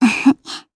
Gremory-Vox-Laugh_jp.wav